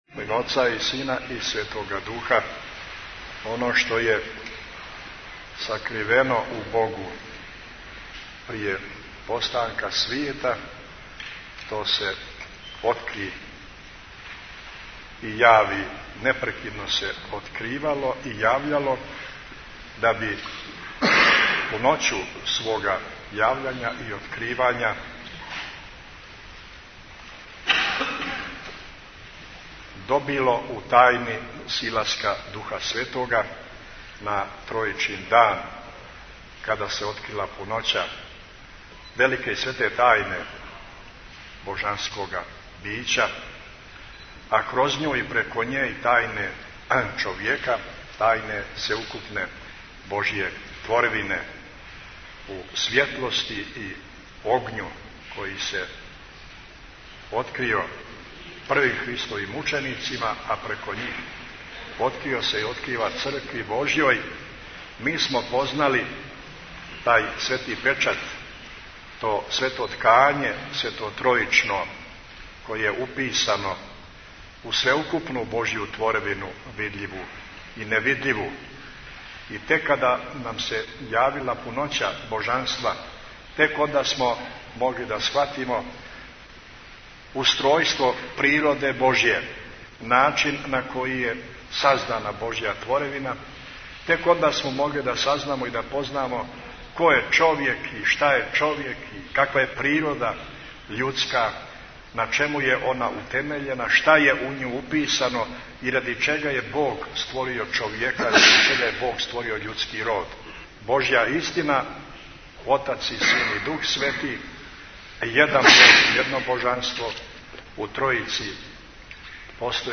Бесједе